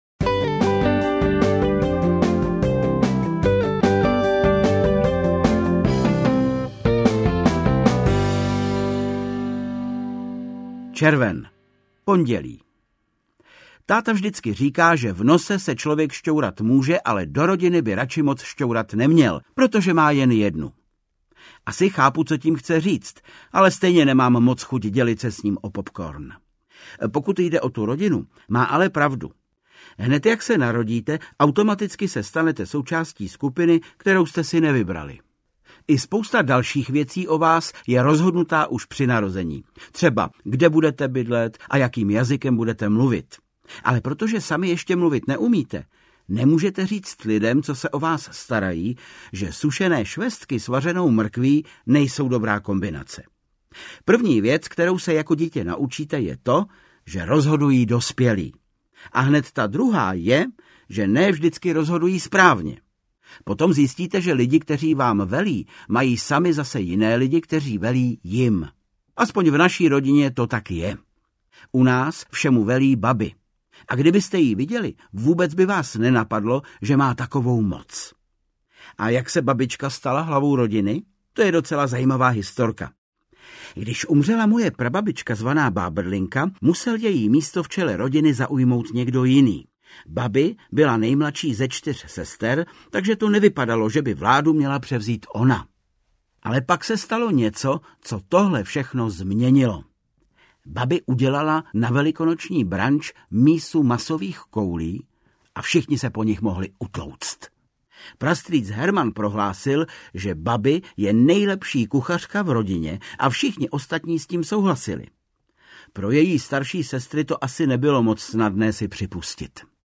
Interpret:  Václav Kopta